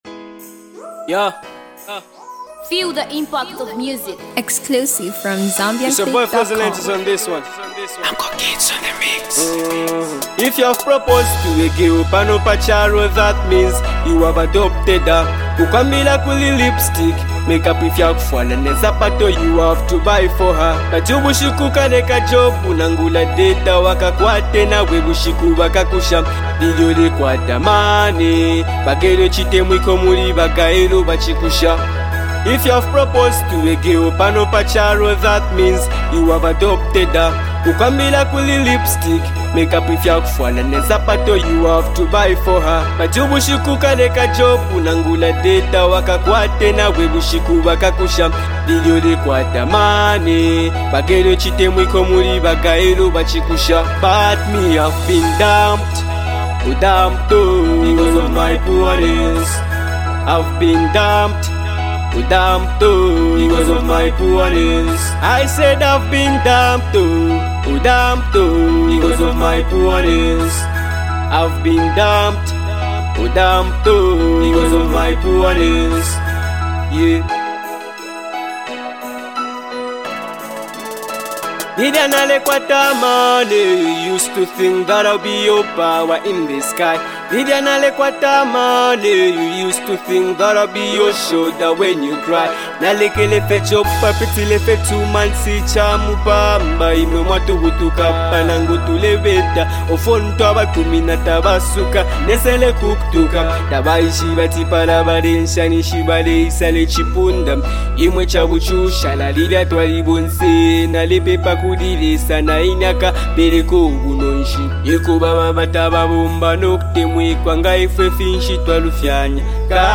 rocking song